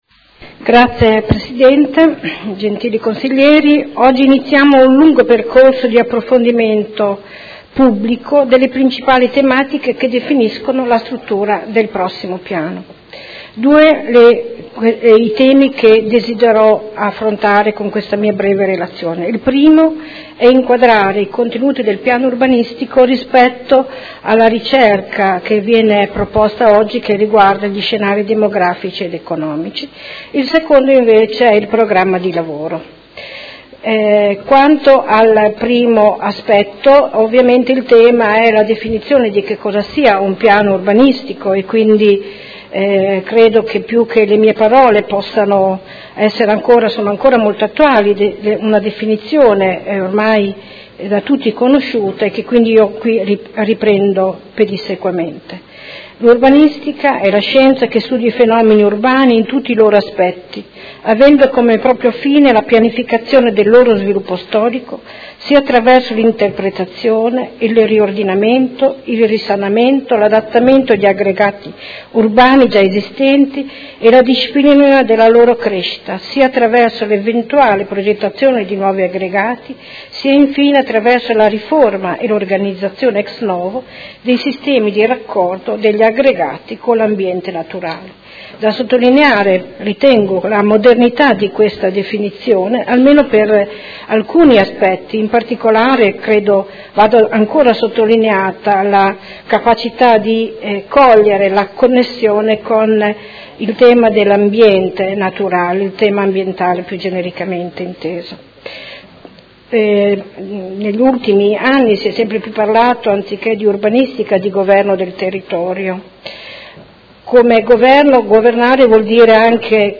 Anna Maria Vandelli — Sito Audio Consiglio Comunale